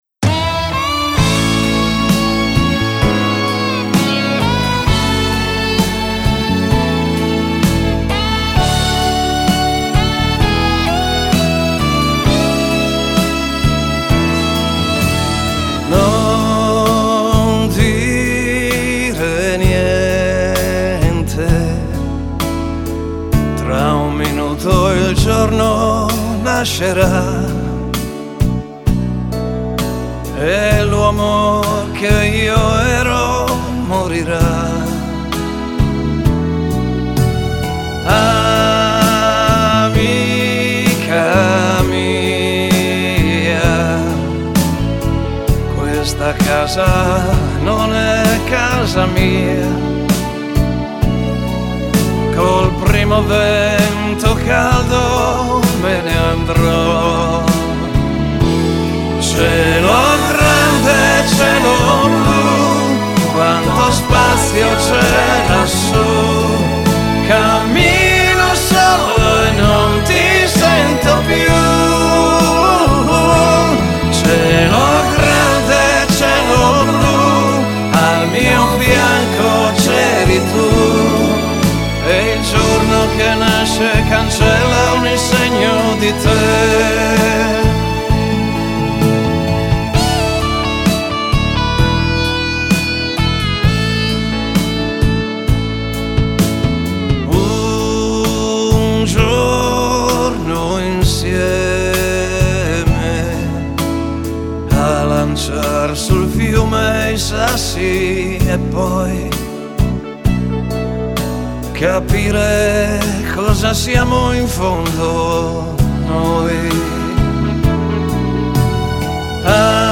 Ora la mia voce è più matura e anche più distesa.